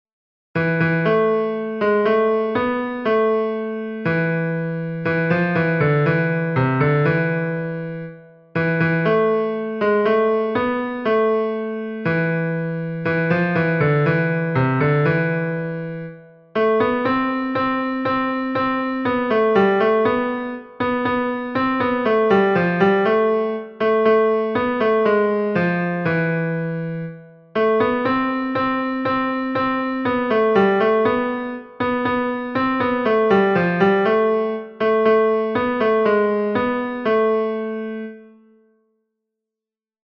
Ténors 2